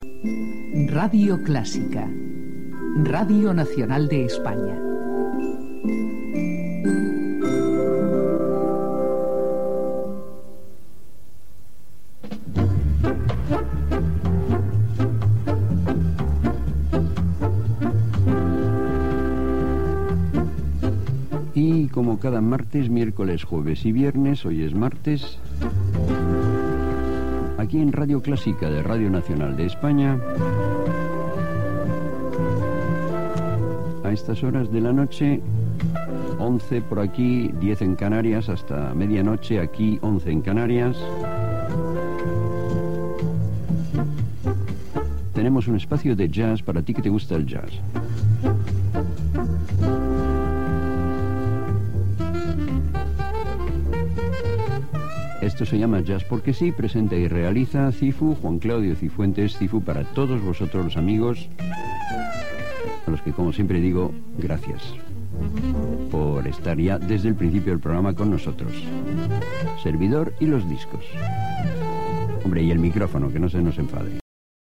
Identificació de l'emissora i presentació inicial del programa.
FM